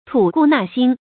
tǔ gù nà xīn
吐故纳新发音
成语正音 吐，不能读作“tù”。